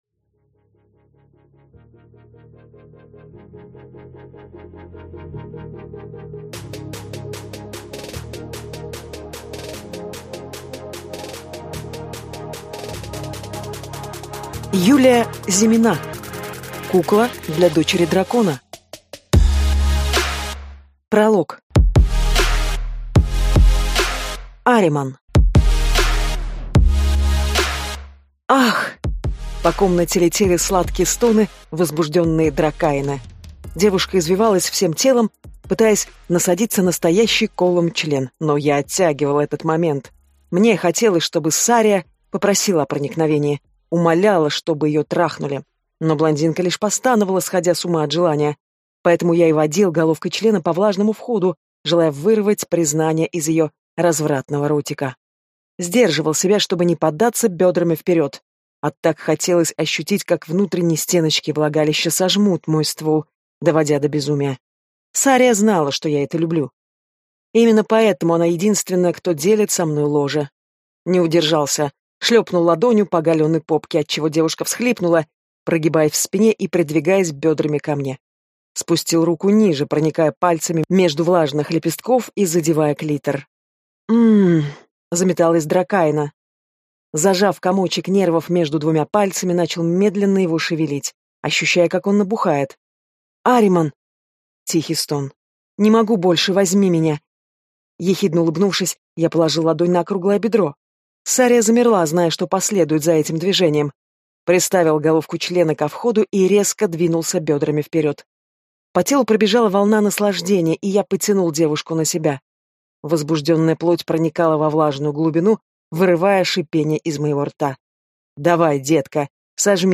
Аудиокнига Кукла для (дочери) дракона | Библиотека аудиокниг